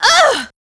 Ripine-Vox_Damage_03.wav